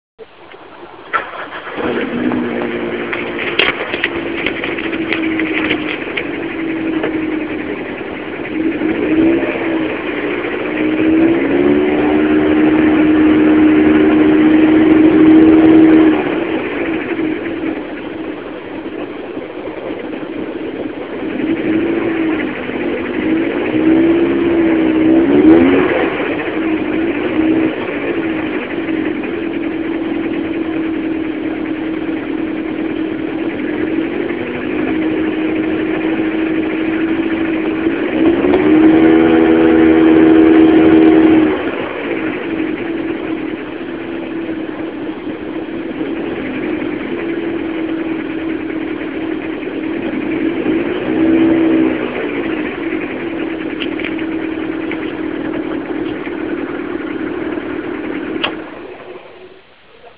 Anzi, stamani accelerando un po' dava quasi l'impressione che tendesse "ad ingranare"... bruttissimo rumore cmq...
Sono riuscito a fare una specie di registrazione (la qualità è quella di un celulare...) per chi volesse farsi un'idea.
Si sente soprattutto nelle fasi di rilascio e/o quando il motore scende d giri.
Scherzi a parte, il cell ha abbastanza ingigantito alcune frequenze facendo sembrare che ci fosse più rumore di quello che c'era...
Ad ogni modo il "noise" è ben udibile quando sono in rilascio ed il motore si sta avvicinando al minimo...